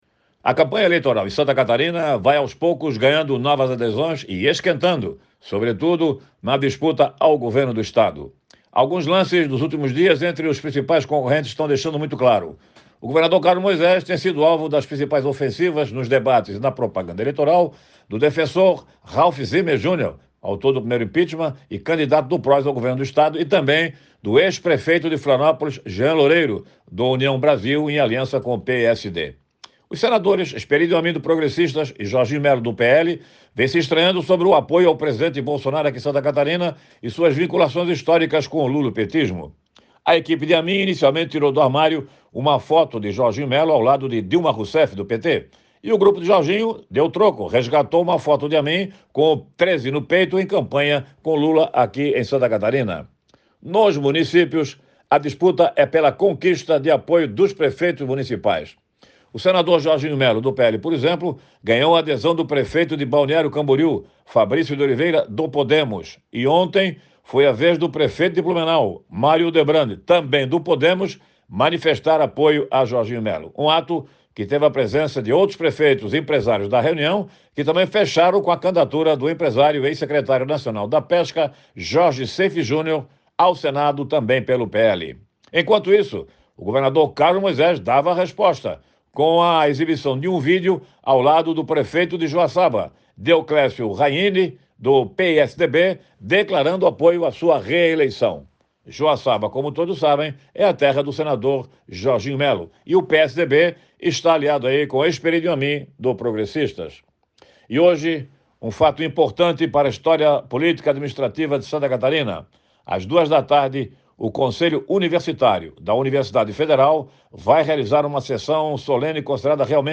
O jornalista comenta sobre as parcerias firmadas na campanha eleitoral e destaca um importante protagonista na história política e administrativa de Santa Catarina; confira